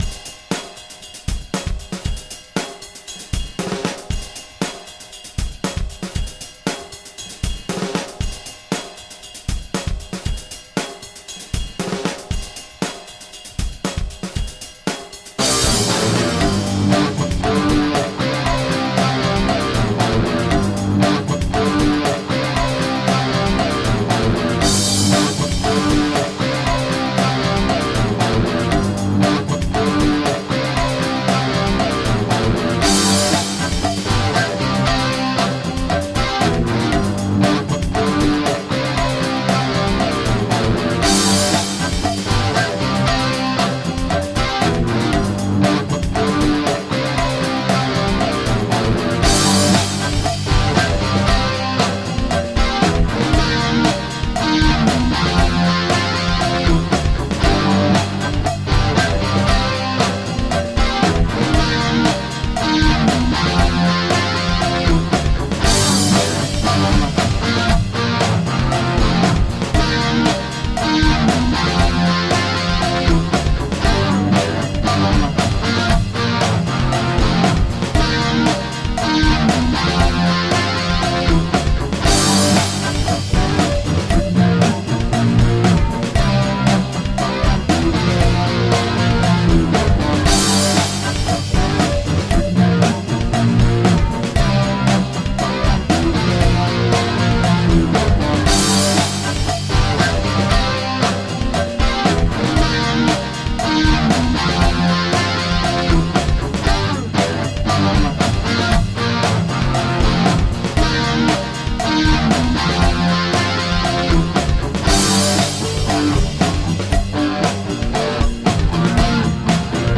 Instrumental hard rock.
Tagged as: Hard Rock, Metal, Instrumental